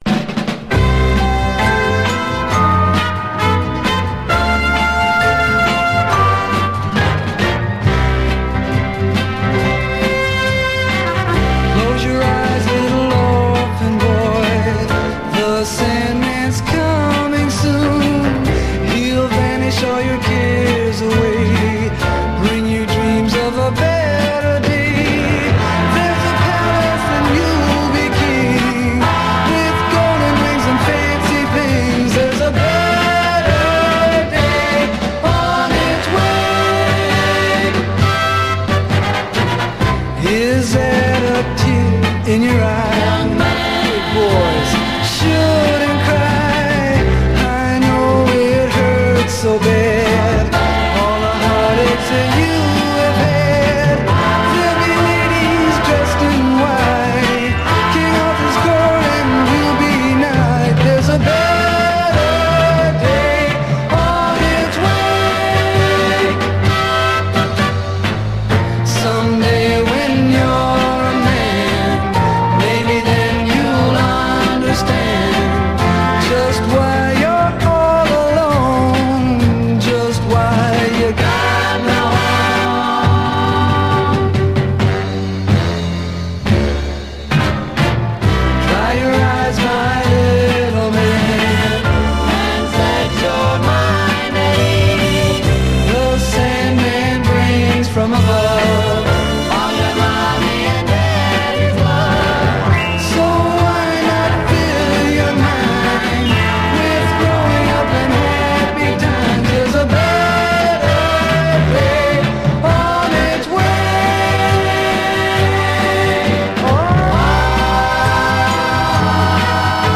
SOUL, 60's SOUL, 7INCH
ノーザン・ソウル・シングルをいくつも残した白人男女混成グループ！
ガーリーな可憐さエレガントさで舞う、最高の胸キュン・チューン！